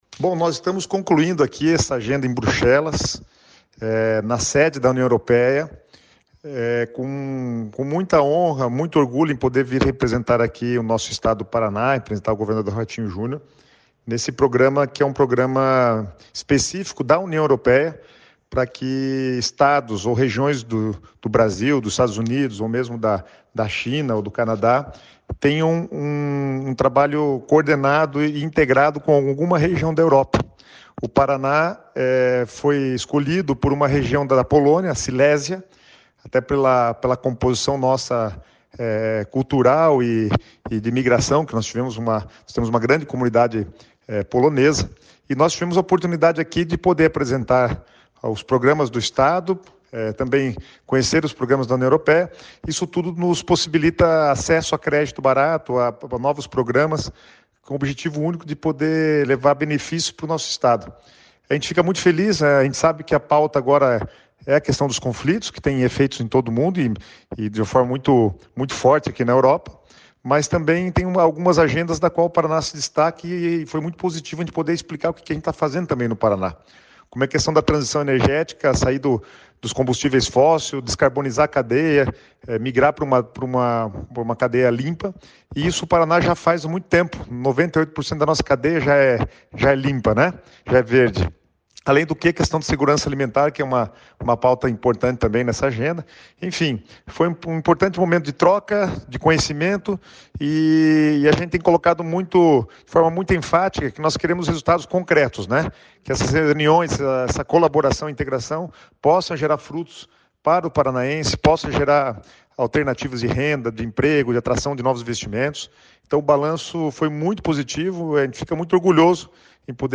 Sonora do secretário do Planejamento, Guto Silva, sobre a participação do Paraná no evento da Cooperação Internacional entre Cidades e Estados na Bélgica